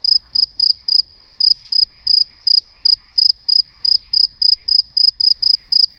（3）高采样率音频，高保真音质：行业大部分为16kHz、32kHz，Vidu做出了商业领域全球首家支持48KHz的文生音效系统，解决音效刺耳、压缩失真、声音不自然流畅等问题。
可以感受下用该功能生成的48kHz高保真蟋蟀声、“世界毁灭”氛围感音效：
提示词：蟋蟀声